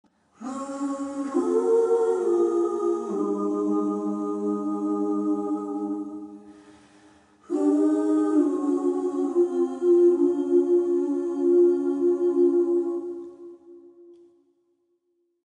sorry for got to inlude the mp3 vietnames call " nhac be`"